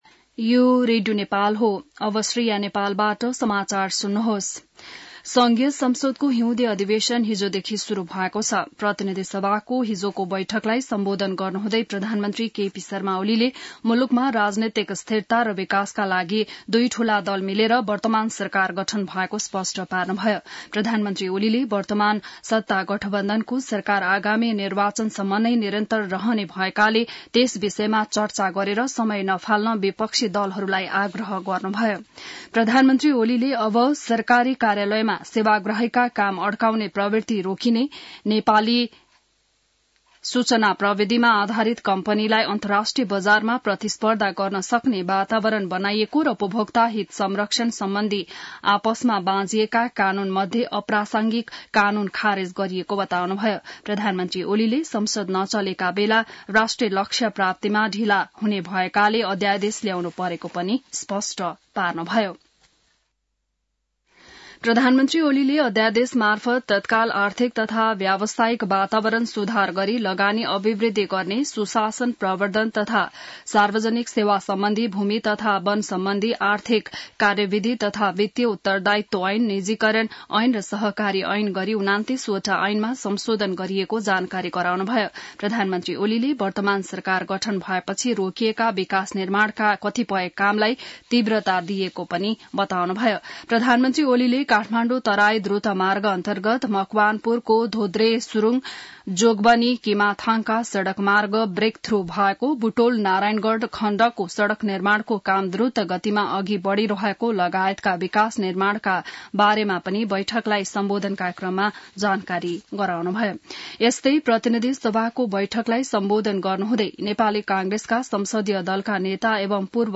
बिहान ६ बजेको नेपाली समाचार : २० माघ , २०८१